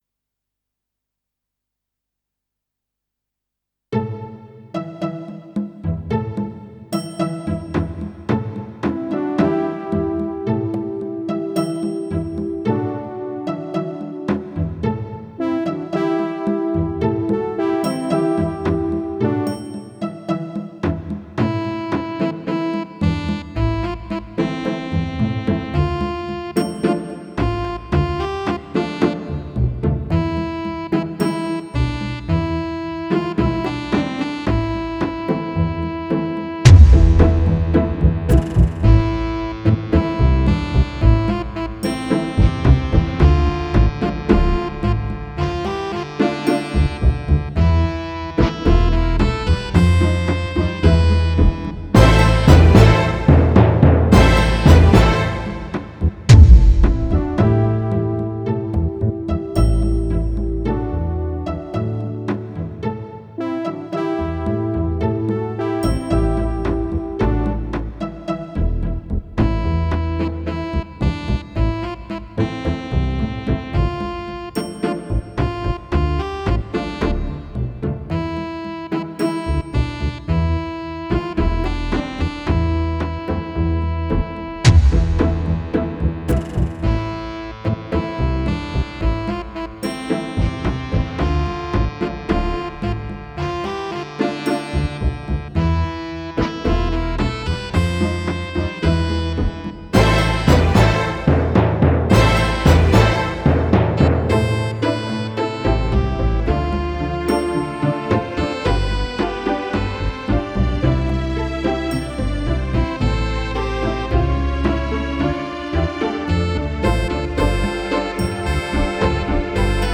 The other Old Testament character songs will also appear eventually in karaoke format with words to sing along, so you can continue to plumb the depths of rich veins of Old Testament scripture.
For the purpose of this song make sure you pronounce the name Hag-gay-eye in order to make the words fit.